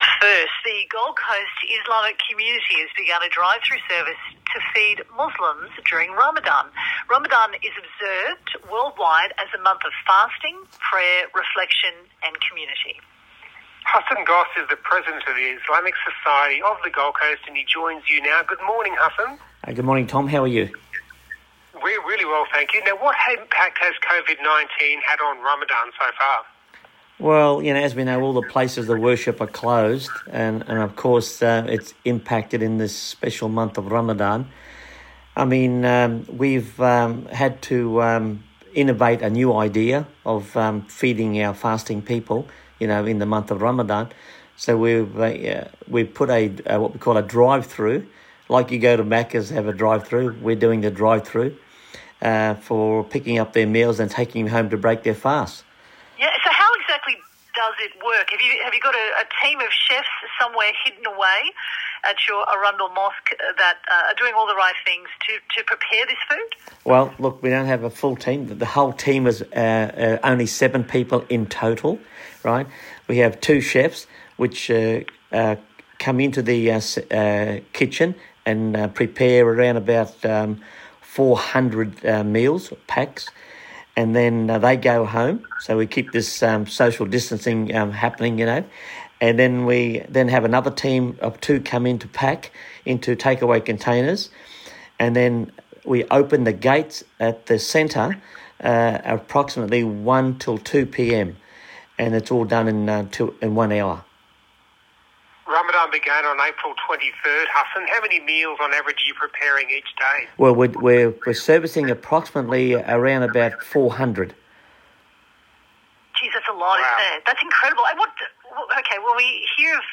ABC Radio interview